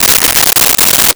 Wrapper Opened 02
Wrapper Opened 02.wav